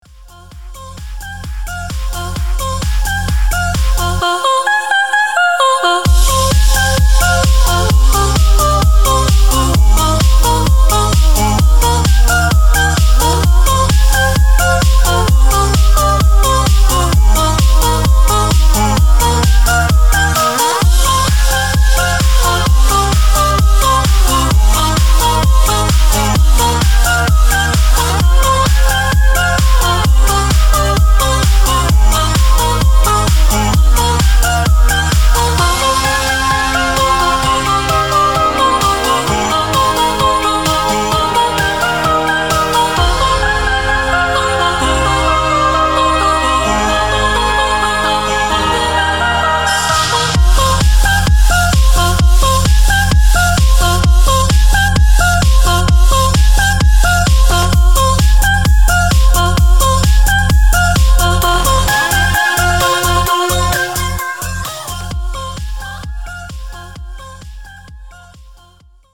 • Качество: 320, Stereo
красивые
женский вокал
club